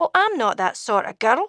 Sound Bites
Here are a few .wav files of Annah speaking. Her voice is done by the talented Sheena Easton, and she has an awesome accent.